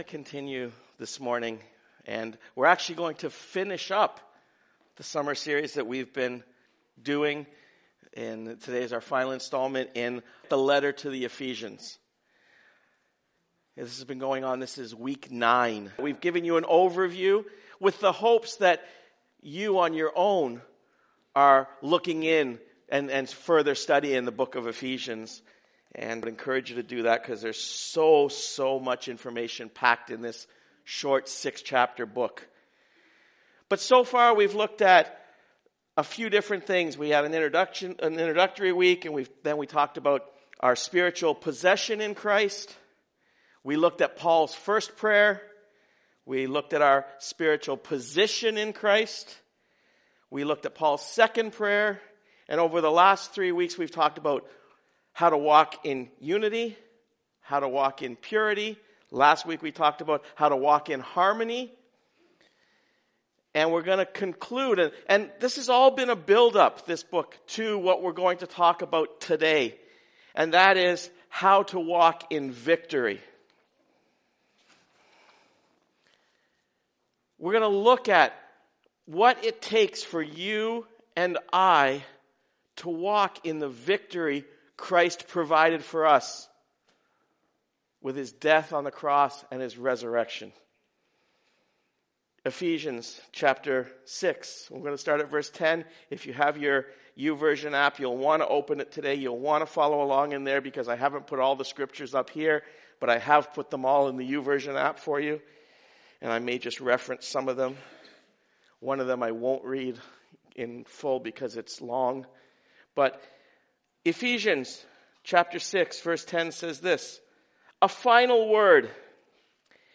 Sermons | Highway Gospel Church